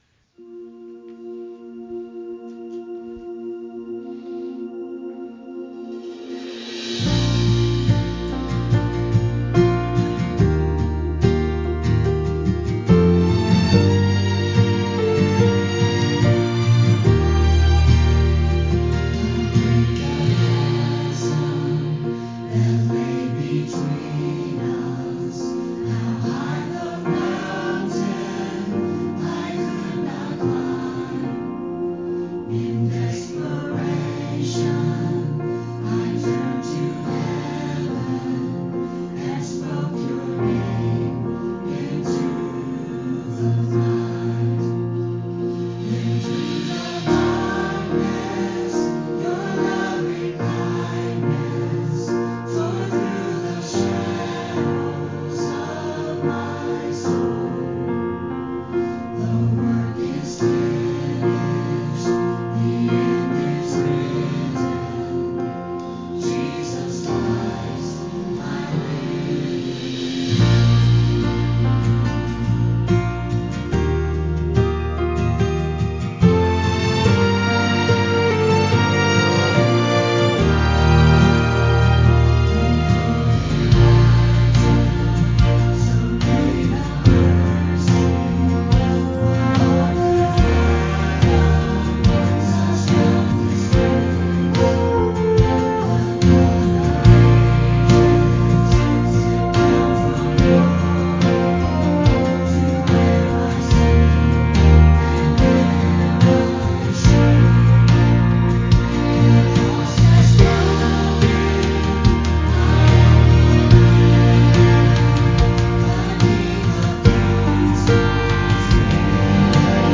sermonJan26-CD.mp3